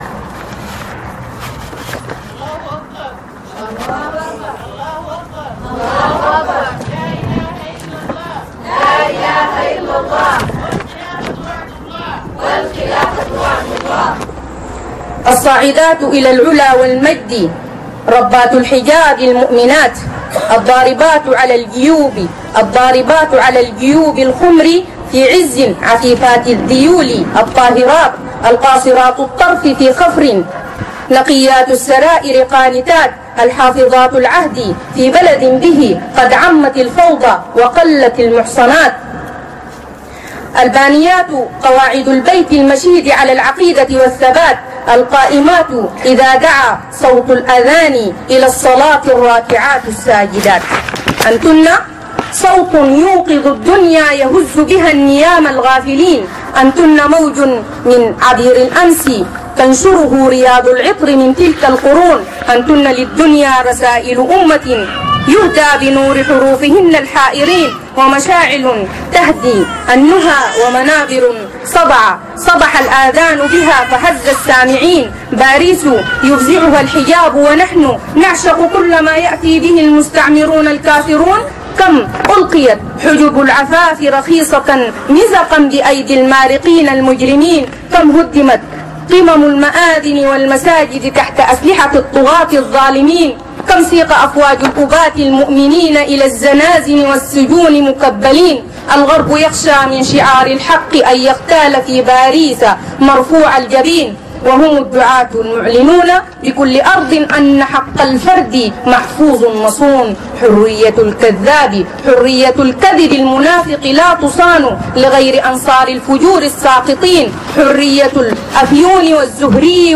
قصيدة بعنوان - الصاعدات إلى العلا والمجد تلقيها الأستاذة